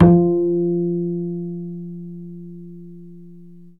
DBL BASS GN3.wav